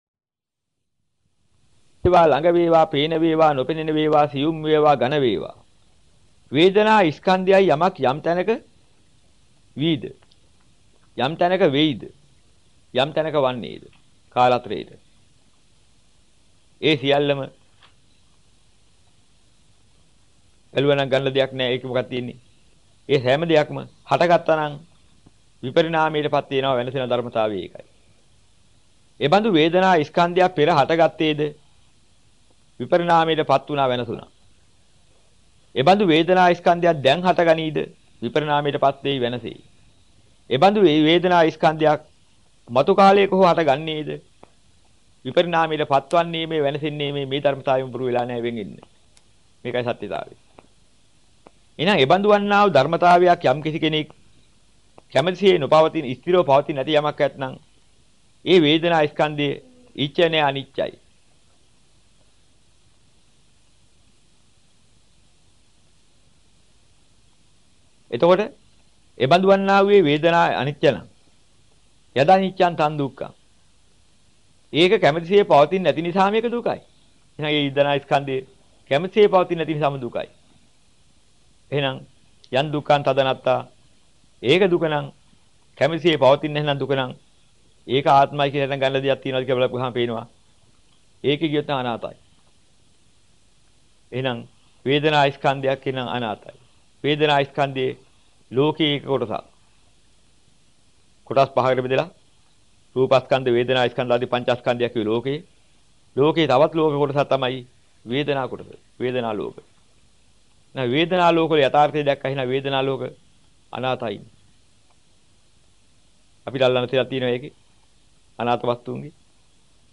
දේශනාවට සවන් දෙන්න (අහන ගමන් කියවන්න)